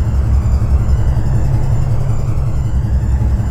special_jetpack.ogg